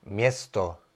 Ääntäminen
France: IPA: /vil/